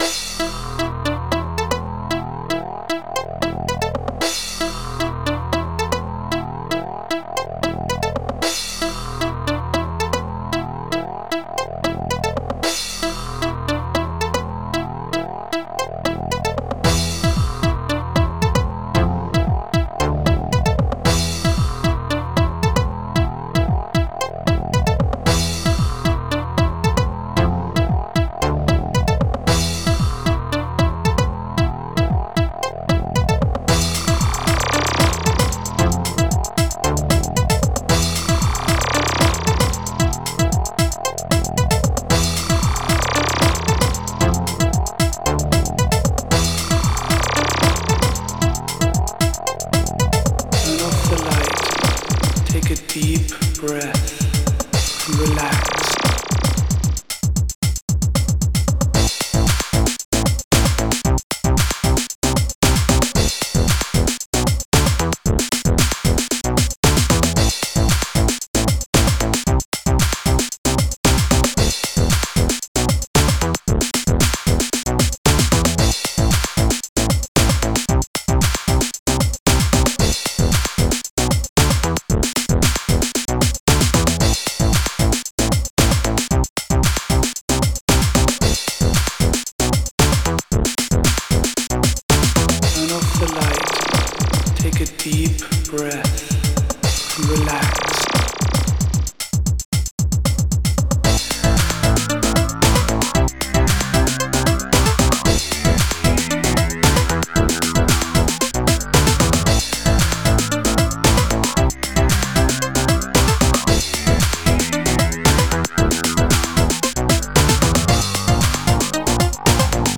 Extended Module
Type xm (FastTracker 2 v1.04)